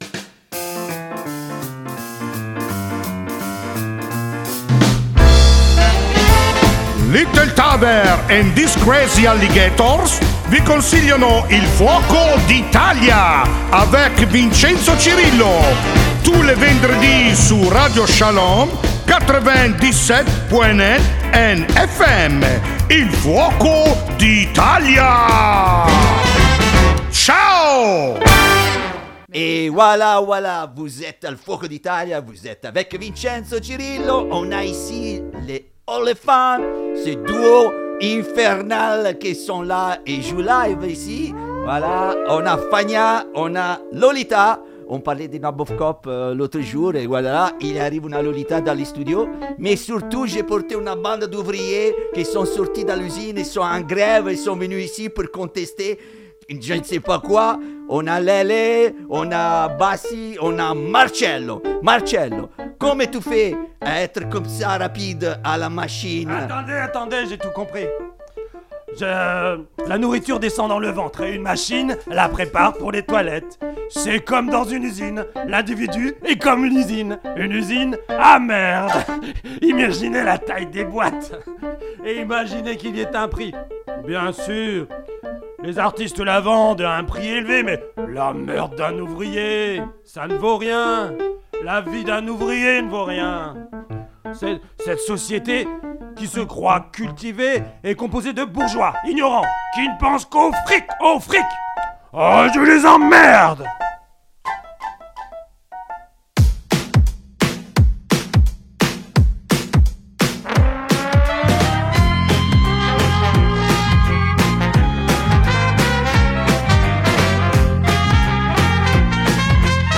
Plongez avec elles dans la piscine de leur univers poétique électrique et décalé : Chant, Piano, MPC, Trompette ou encore percussion.